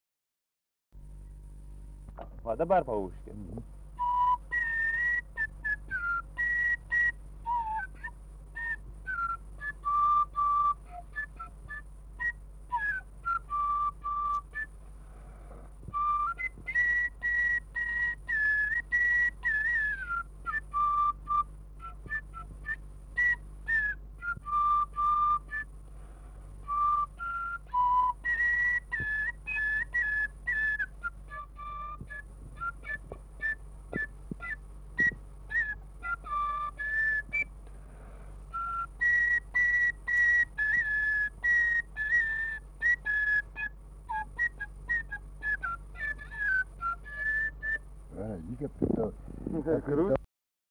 Tipas rauda Erdvinė aprėptis Girios (Hiry), Baltarusija
Atlikimo pubūdis vokalinis